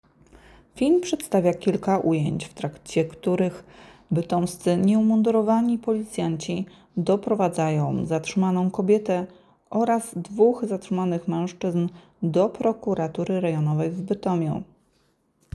Nagranie audio Deskrypcja filmu